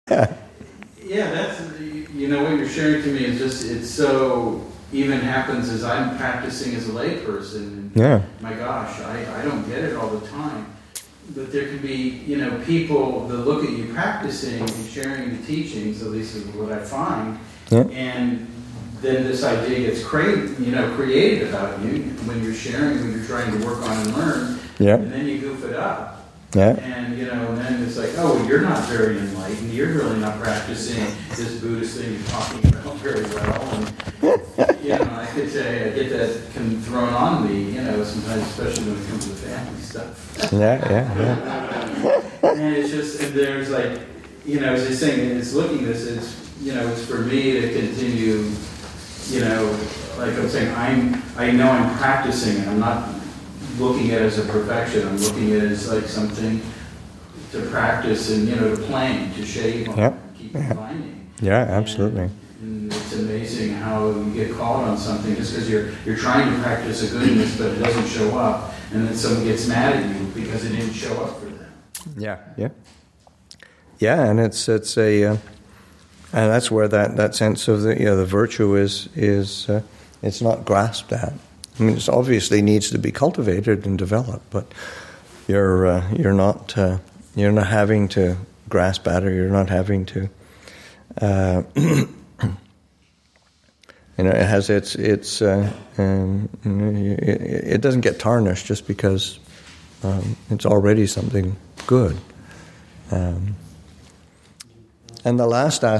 Abhayagiri Buddhist Monastery in Redwood Valley, California and online